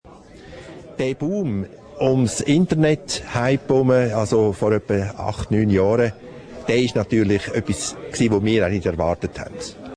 Abt. O-Ton